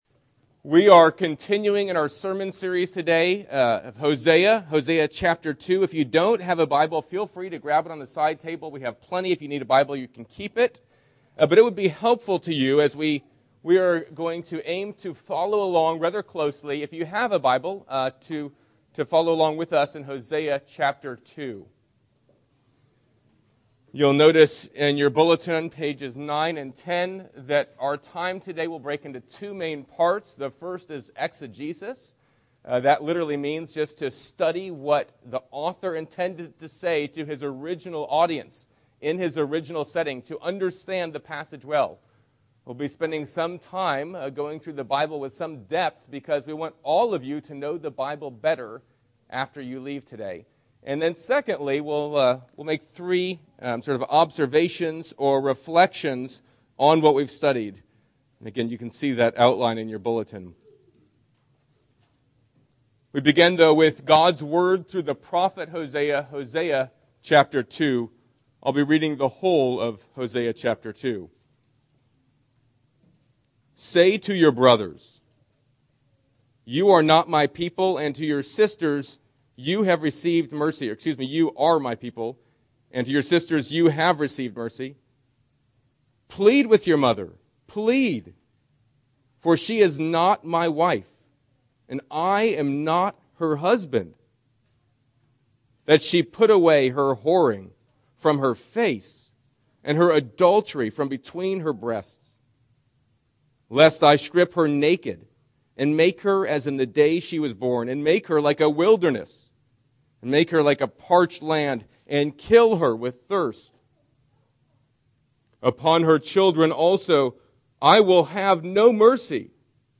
Sermons - Hosea < King of Kings, PCA